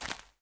StepGrass.mp3